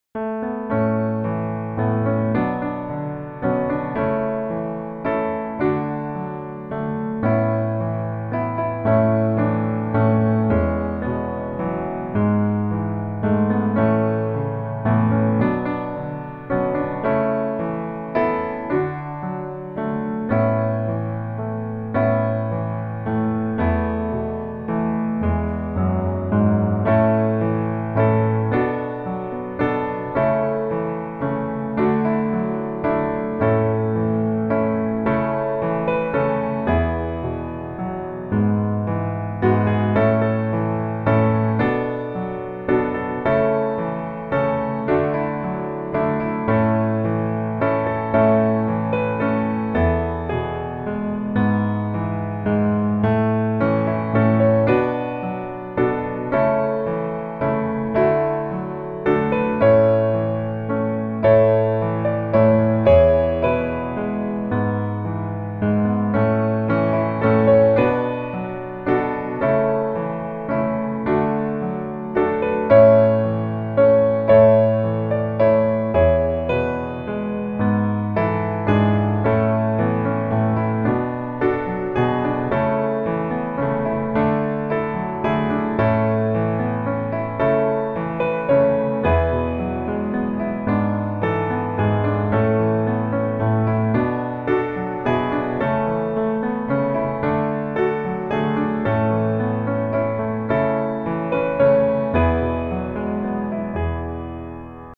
A Major